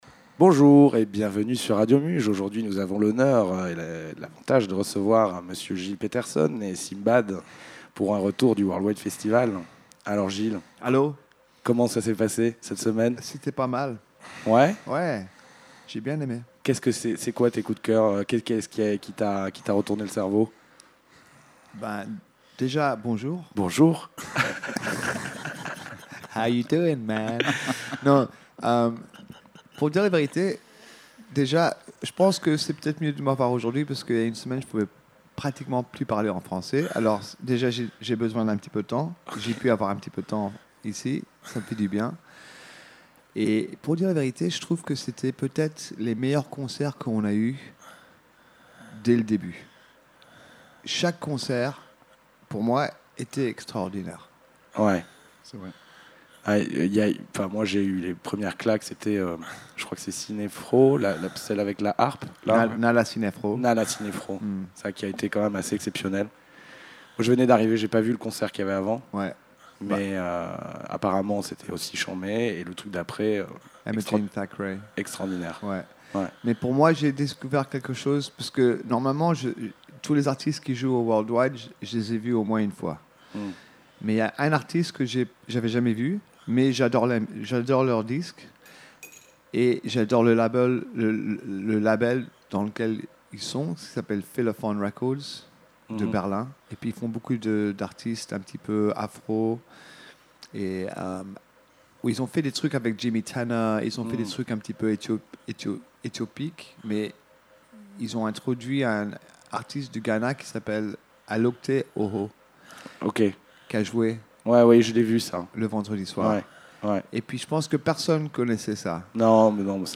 ITW
Rencontre et Interview exclusive
pour un retour de la 16e édition 2022 du Worldwide Festival enregistrée le 11/07/2022 @ Radio Muge Studios.